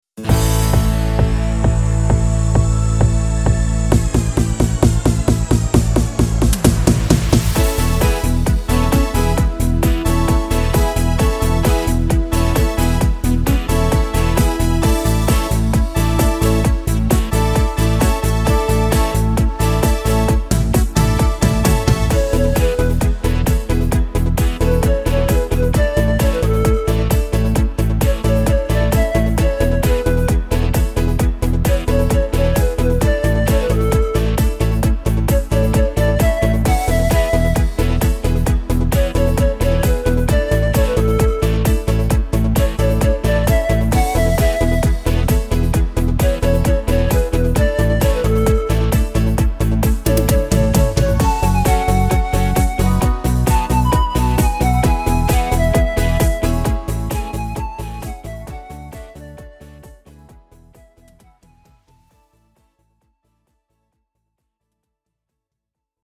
Tempo: 132 / Tonart: A-Dur
– 1 x MP3-Datei mit Melodie-Spur